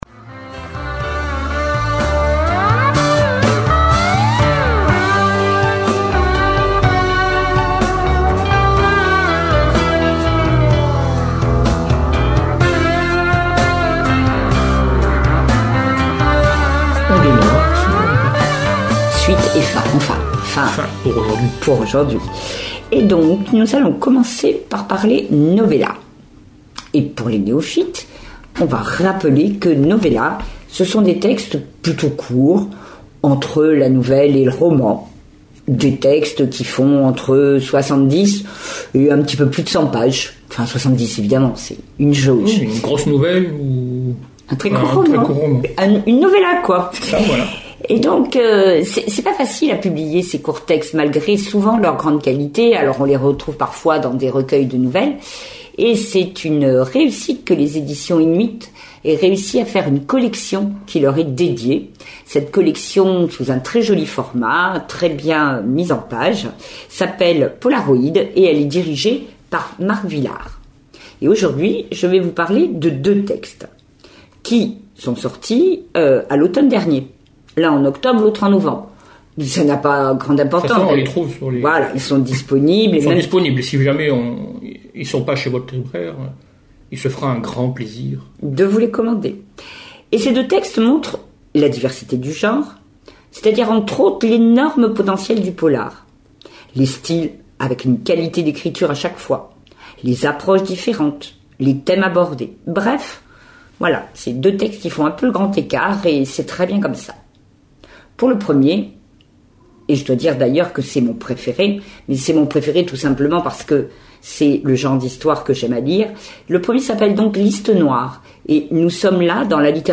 Chronique noire